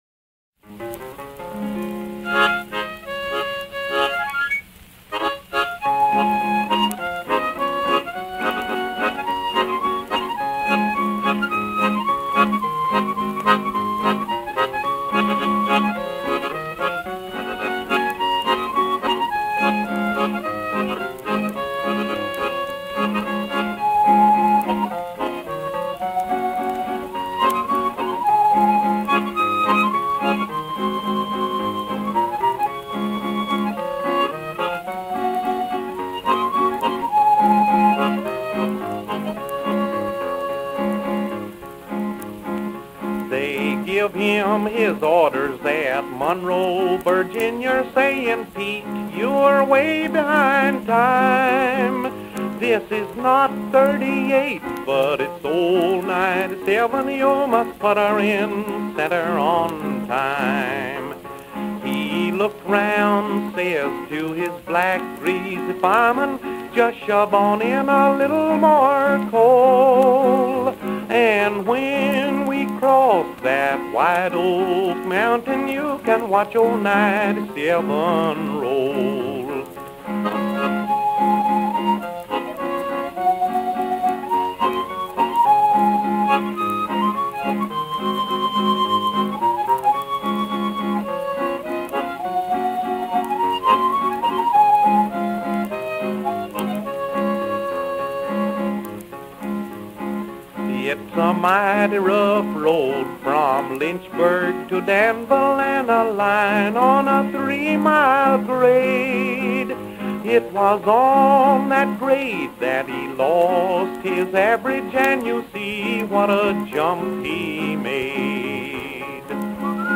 Another country artist